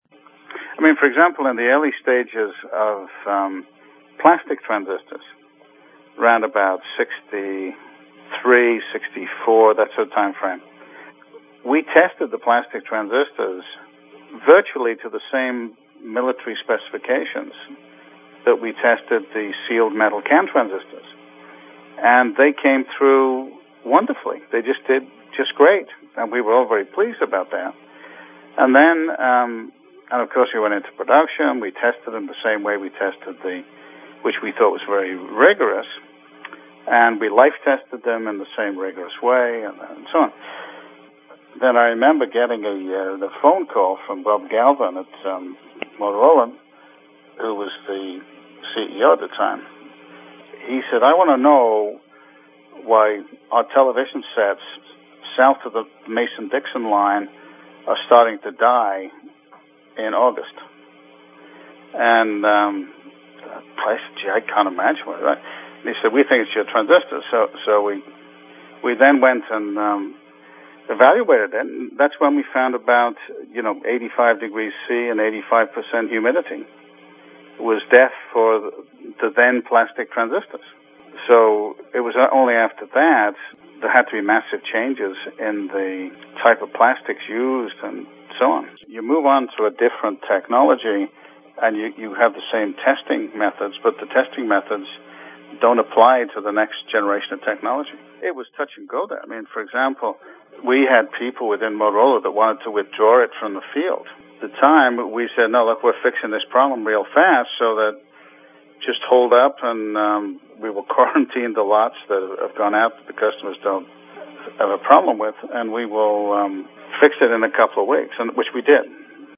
A Transistor Museum Interview